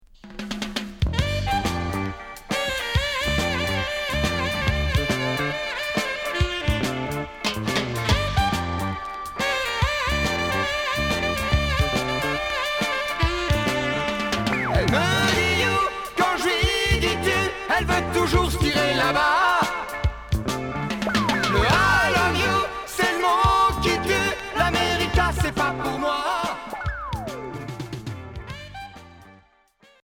Reggae rock variété Unique 45t retour à l'accueil